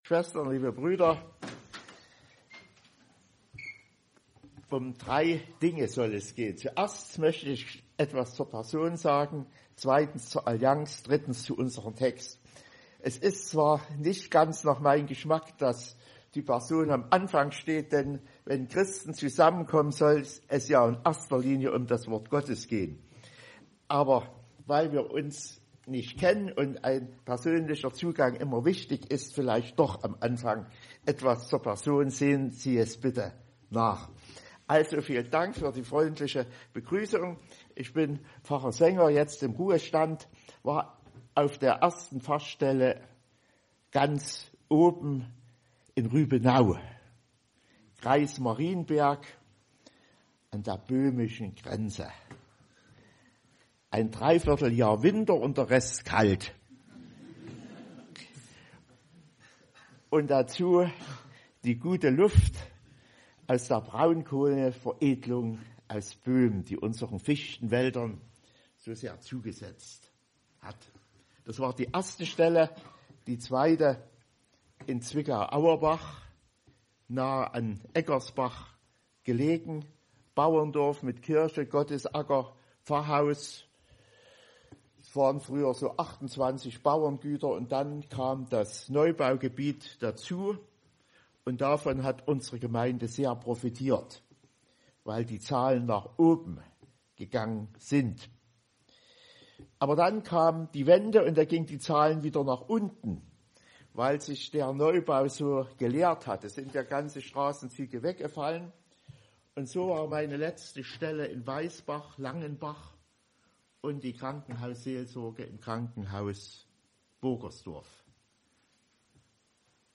Psalm 126,4-6 Gottesdienstart: Allianz-Gottesdienst ...wenn ich an ihren Erlöser glauben soll" hat Friedrich Nietzsche einmal gesagt.